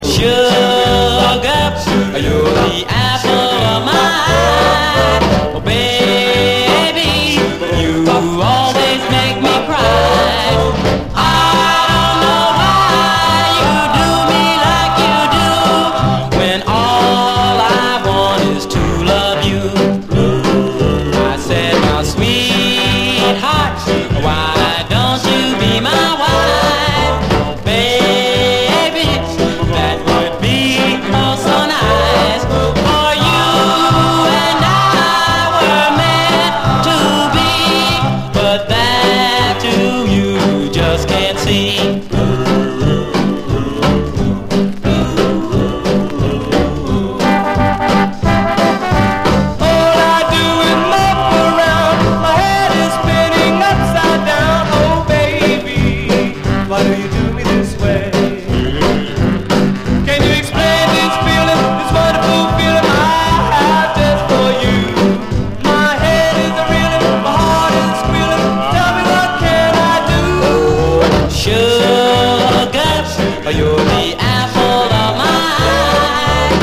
DOO WOP, OLDIES, 7INCH
胸キュンなコーラス・ハーモニーが弾む、文句なしの最高青春ドゥーワップ！
胸キュンなコーラス・ハーモニーが弾む、文句なしの最高青春ドゥーワップです！